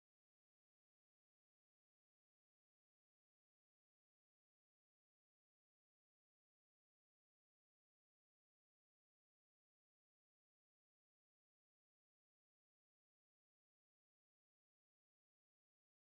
Kindertänze: Tink, tank Tellerlein
Tonart: D-Dur
Taktart: 2/4
Tonumfang: Oktave
Besetzung: vokal